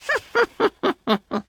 Minecraft Version Minecraft Version latest Latest Release | Latest Snapshot latest / assets / minecraft / sounds / entity / witch / celebrate.ogg Compare With Compare With Latest Release | Latest Snapshot
celebrate.ogg